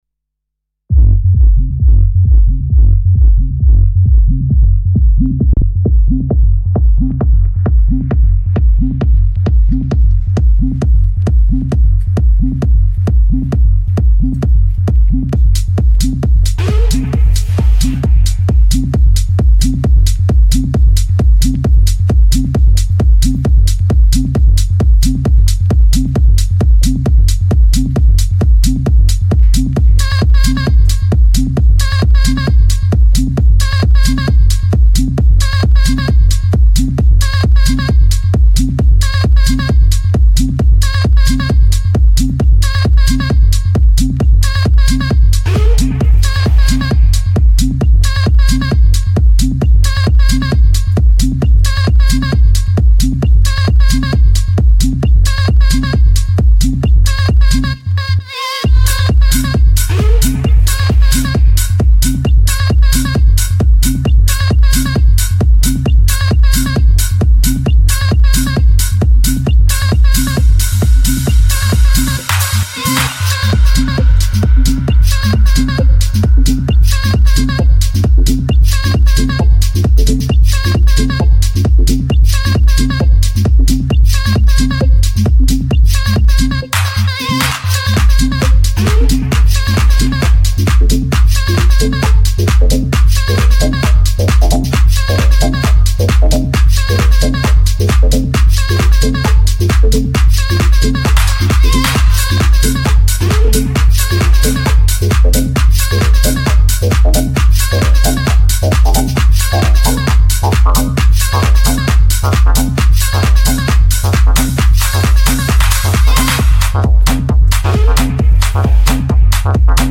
leaves falling, beats dropping